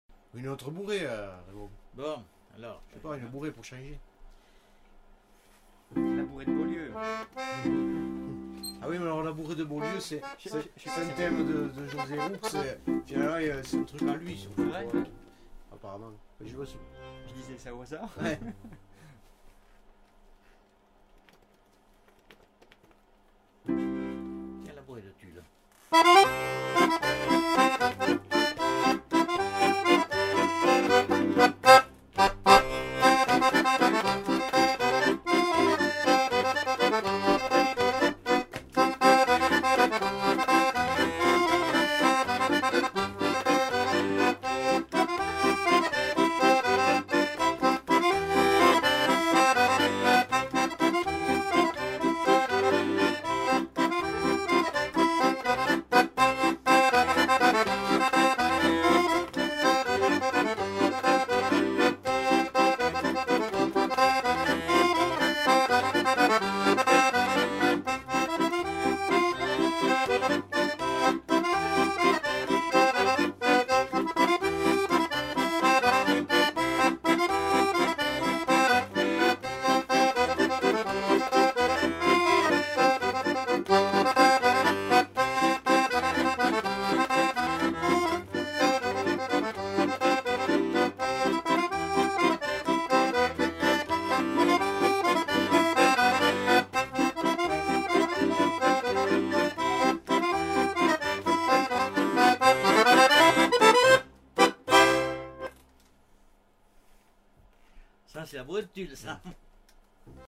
Aire culturelle : Quercy
Lieu : Altillac
Genre : morceau instrumental
Instrument de musique : accordéon chromatique
Danse : bourrée